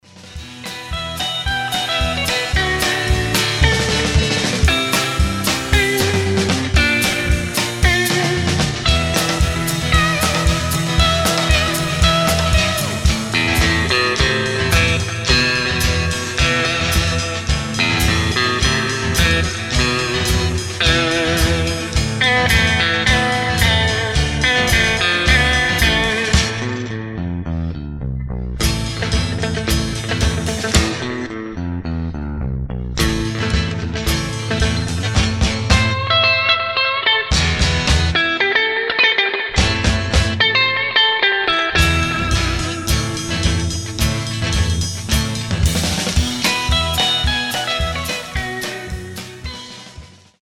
I wanted to share with you a sound file from our rehearsal (we are called so far "International Men Of Mystery") recorded with a Tascam? recorder (I am not sure what it is, but it's a small portable unit).
It's the first and only take, and you will hear mistakes, but I believe the guitar sound is strangely close to Hank's original 9if you use good heaphones), not the playing unfortunately.
This is not the studio. but a tiny rehearsal room, we didn't even know the drummer had pushed the recording button on his recorder.
For a casual and unprepared recording, that's a remarkably authentic sound on my headphones - I'd like to hear more.
what looks like a Fender Deville 4x10 amp, but brown color, it's the rhythm guitarist amp, I didn't pay attention to the model.